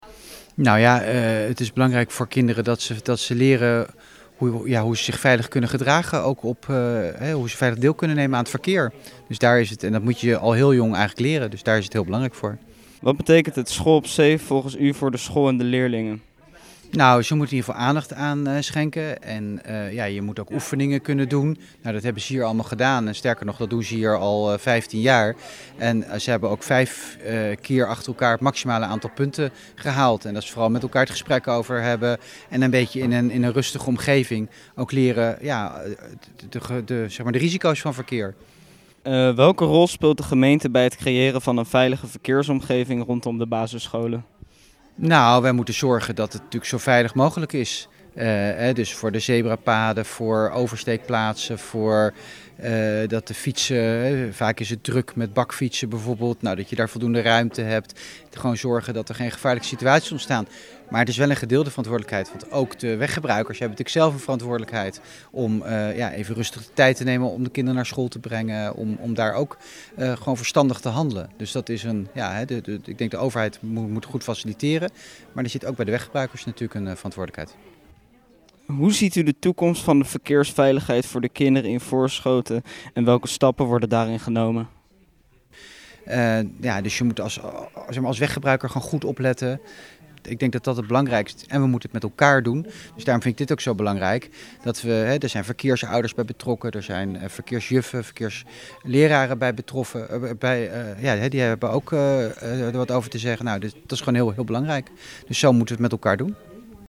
sprak met wethouder Hubert Schokker over School op Seef.